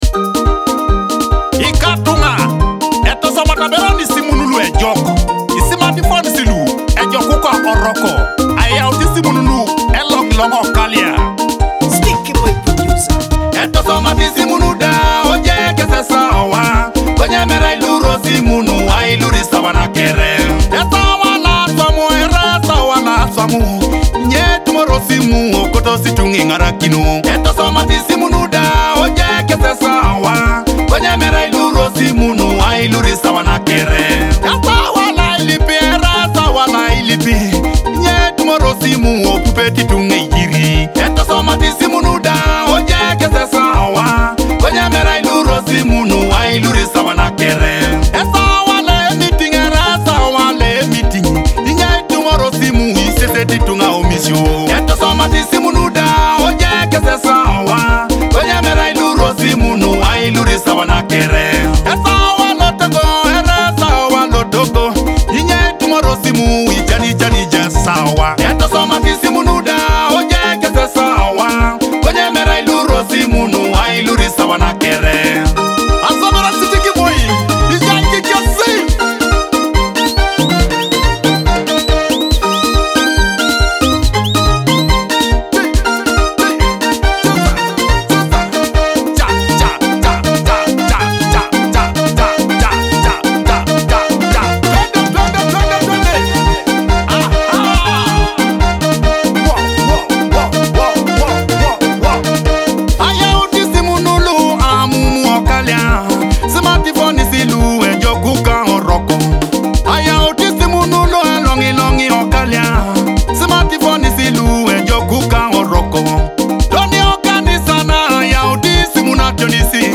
Teso music hit
authentic Teso rhythms
catchy melodies and rhythmic beats